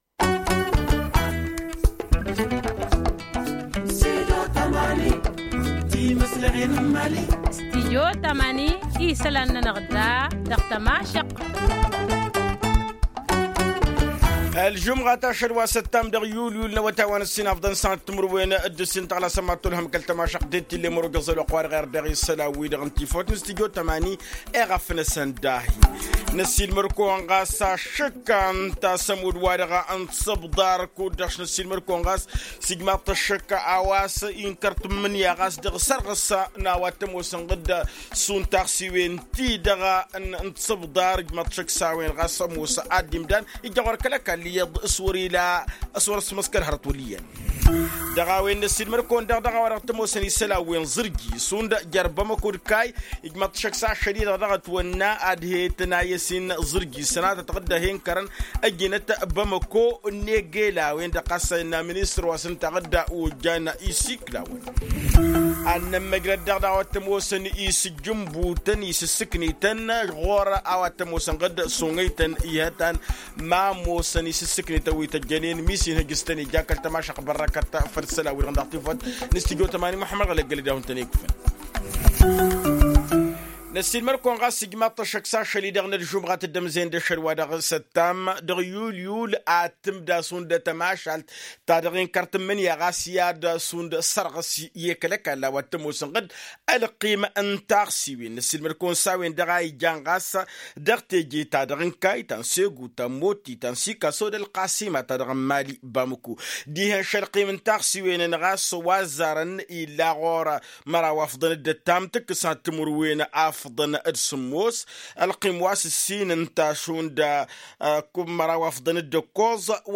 Un reportage à suivre dans cette édition.